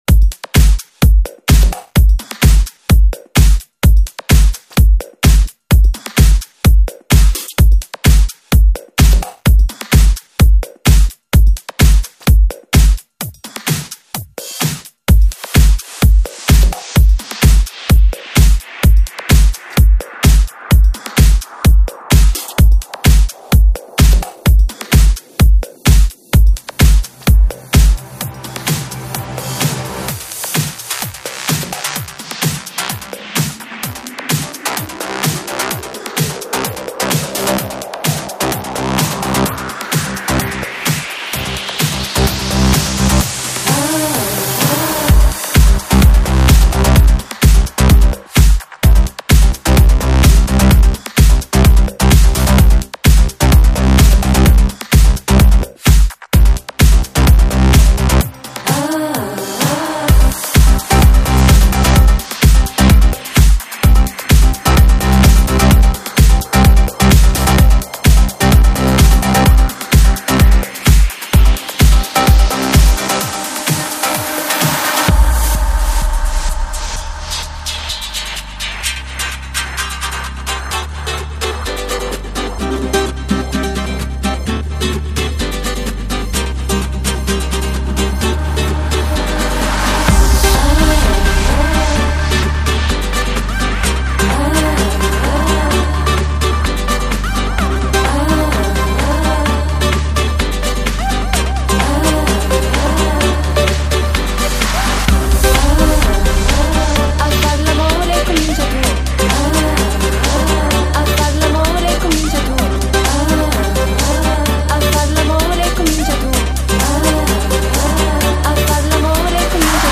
Жанр:Новогодний/Позитивный/Electro/House